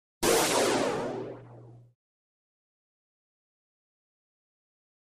Processed Air Release 2; Shorter Than FX 47.[note; This Effect Has A Lot Of Out Of Phase Signal In It.],.